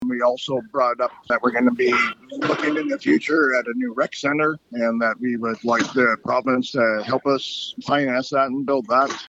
Rocky Mountain House Mayor Shane Boniface speaking about council’s meeting with Municipal Affairs Minister Dan Williams